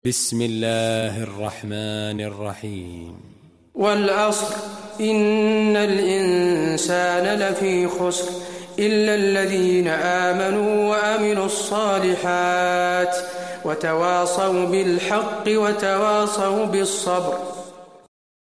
المكان: المسجد النبوي العصر